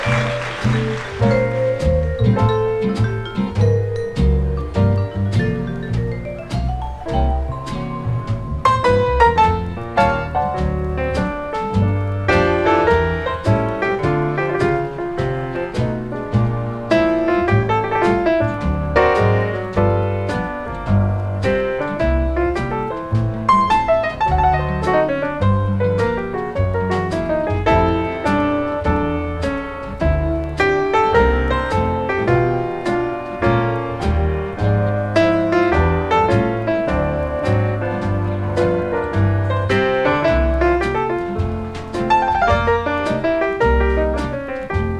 彼らのヒット曲が目白押し、ピアノとスウィートなアンサンブルも聴きどころ多い充実盤。
Jazz, Pop, Easy Listening　USA　12inchレコード　33rpm　Stereo